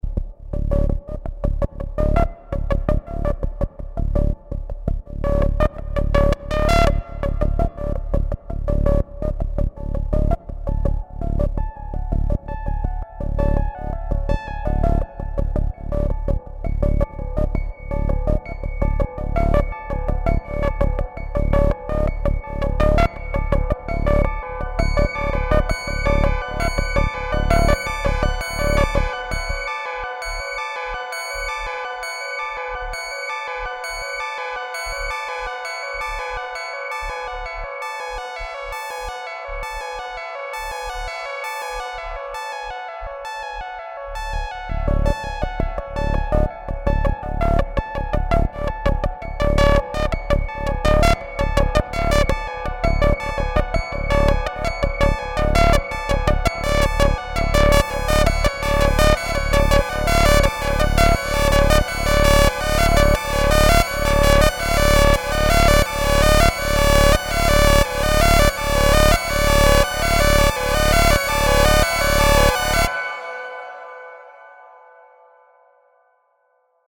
More modular exploration